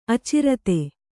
♪ acirate